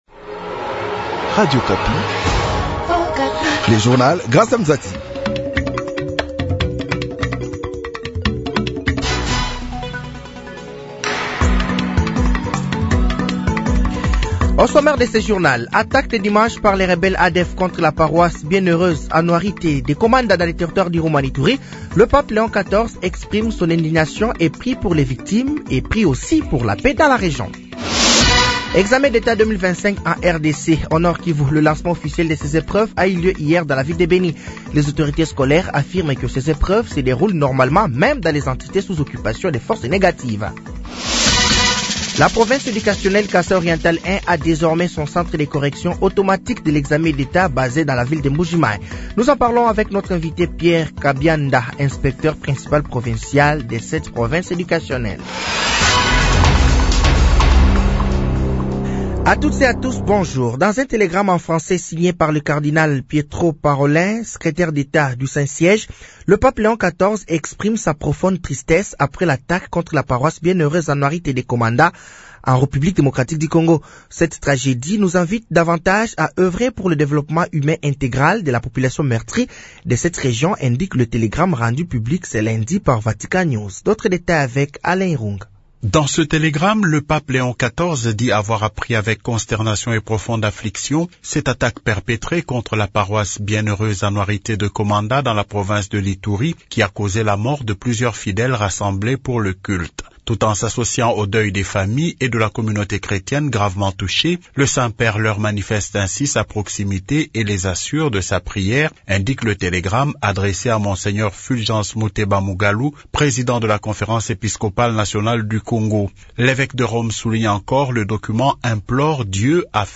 Journal matin
Journal français de 08h de ce mardi 29 juillet 2025